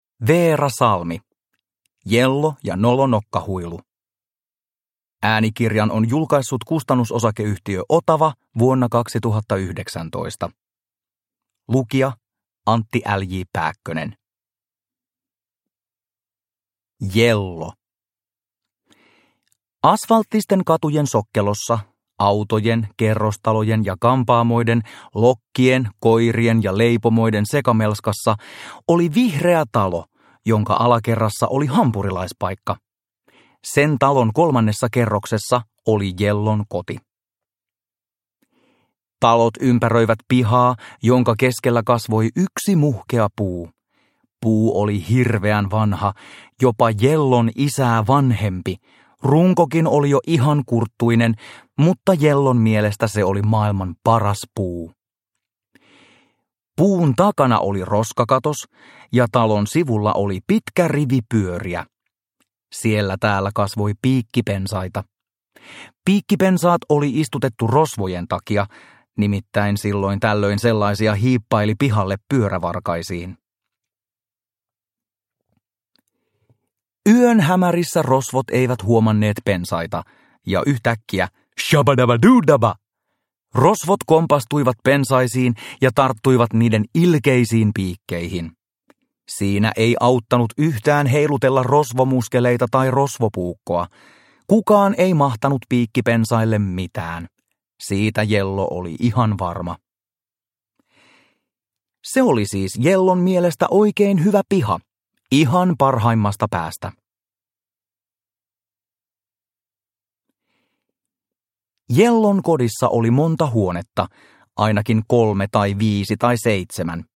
Jello ja nolo nokkahuilu – Ljudbok – Laddas ner